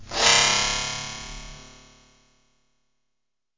科幻外星空间模拟 " 人工模拟的空间声音 13
描述：人工模拟空间声音 通过处理自然环境声音录制而创建Audacity
Tag: 实验 飞船 声景 环境 科幻 无人驾驶飞机 航天器 外星人 人造的 效果 UFO FX 空间 科幻 UFO 气氛